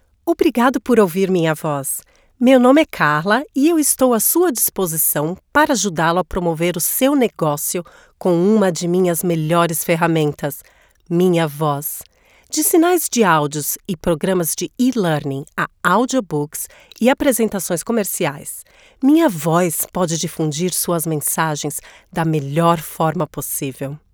Feminino
Apresentação